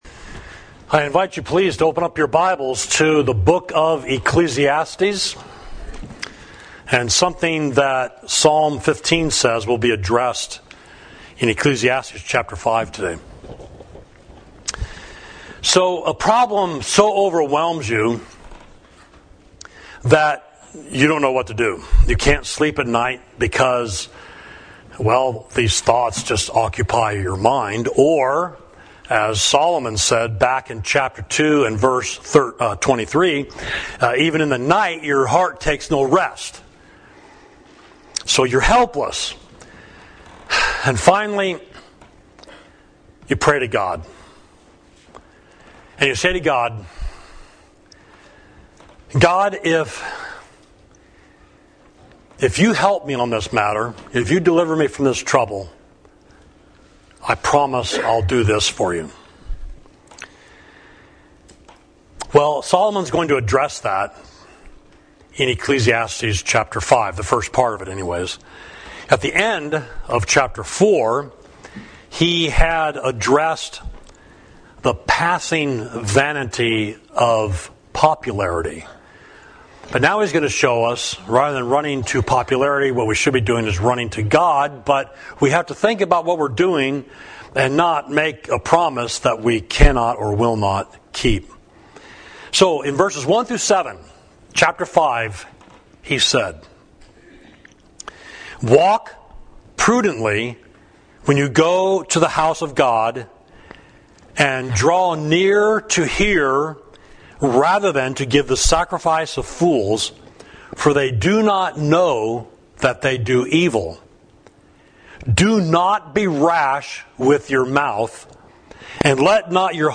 Sermon: Think about Your Promises and Possessions